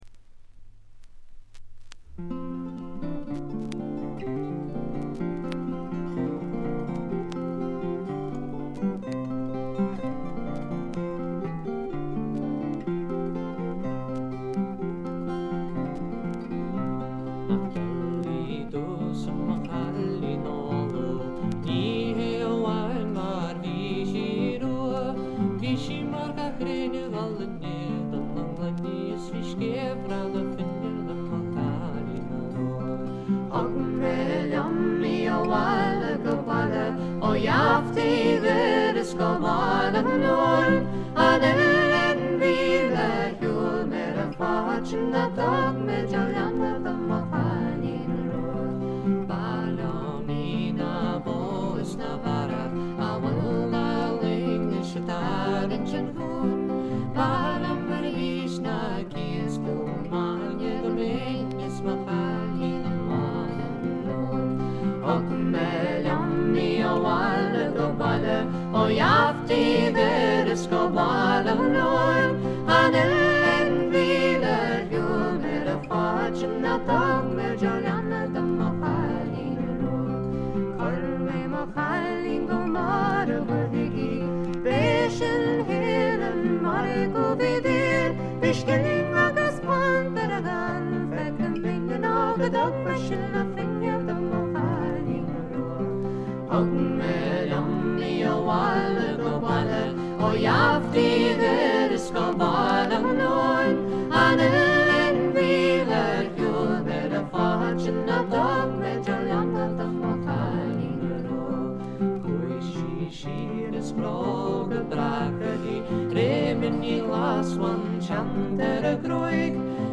全体にバックグラウンドノイズ、チリプチ多め。
まだ学生上がりの若い連中のはずですが、全編にわたってとてもまろやかで穏やかで円熟味すら感じさせるのが素晴らしいです。
試聴曲は現品からの取り込み音源です。